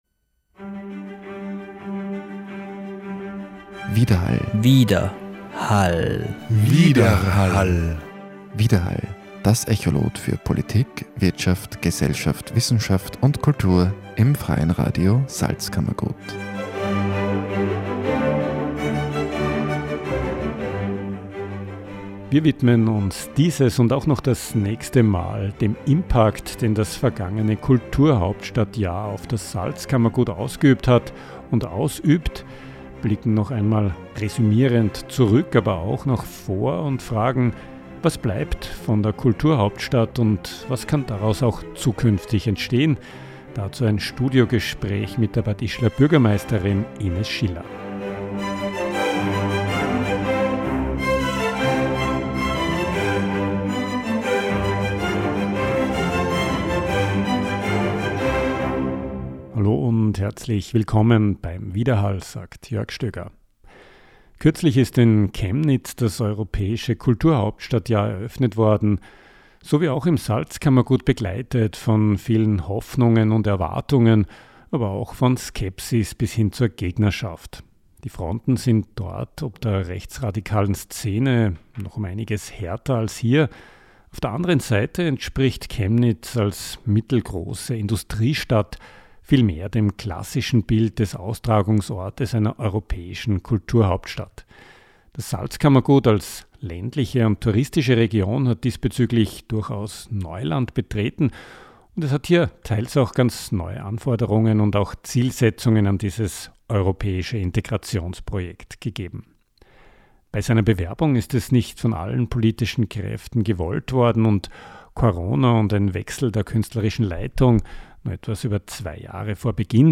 Was bleibt von 2024? – Studiogespräch mit der Bürgermeisterin von Bad Ischl Ines Schiller über die Auswirkungen des Kulturhauptstadtprojekts. Neben einem Resümee des vergangenen Jahres über Gelungenes und weniger Gelungenes sind zukünftige Projekte wie etwa ein projektiertes „Offenes Kulturzentrum“ im Sudhaus Bad Ischl, die Tourismus-Situation und Infrastrukturprojekte wie das Stadtmuseum und die Leharvilla Themen.